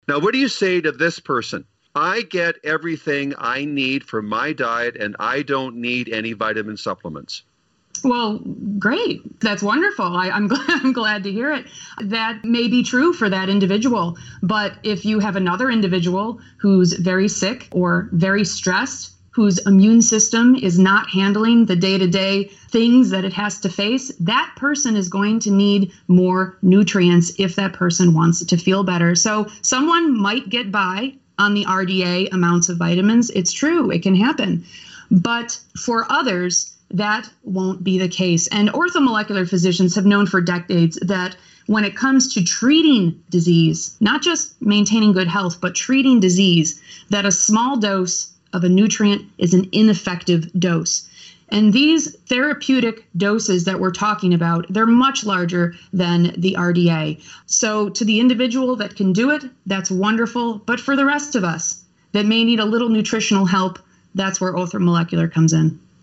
Special Guest Interview